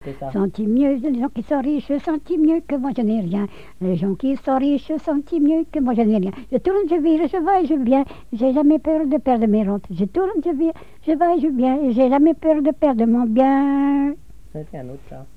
Couplets à danser
danse : branle
collecte en Vendée
répertoire de chansons, et d'airs à danser